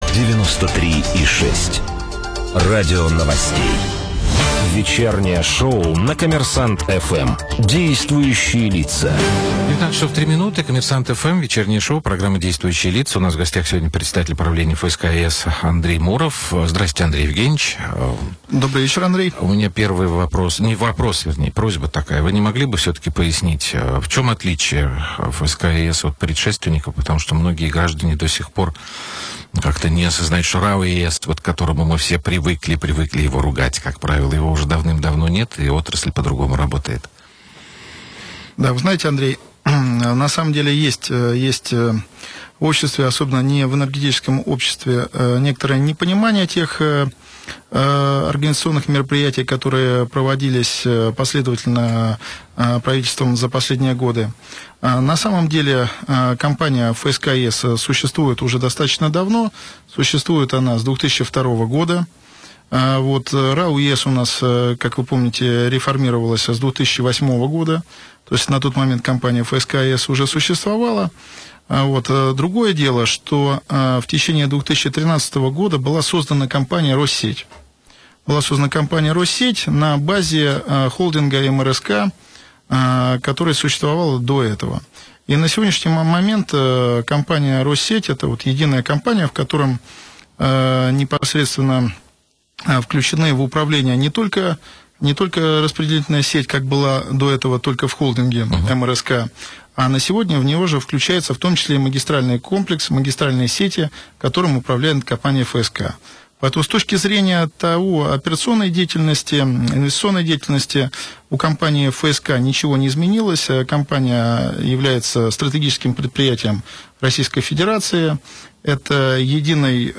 Полная аудизапись интервью